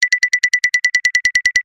Звуки спешки
Звук спешащего мультипликационного персонажа